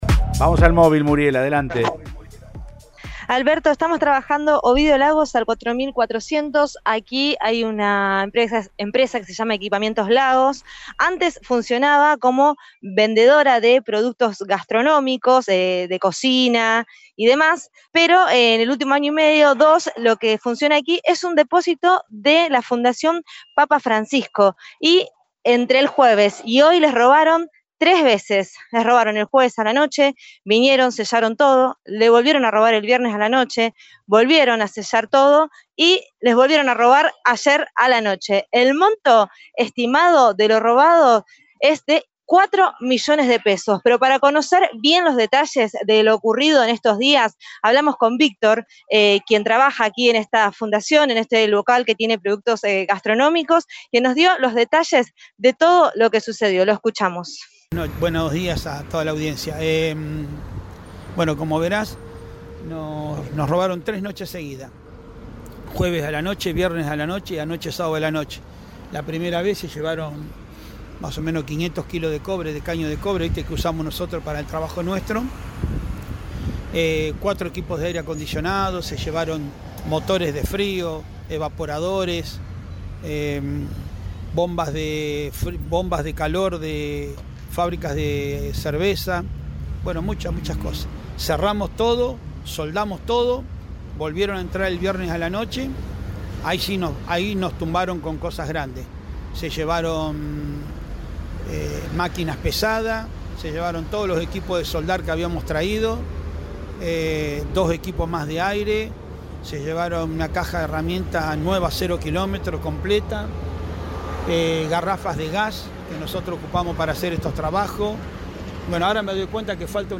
explicó al móvil de Cadena 3 Rosario, en Siempre Juntos, lo sucedido